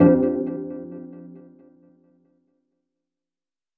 Stat-Decrease.wav